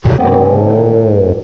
cry_not_heatmor.aif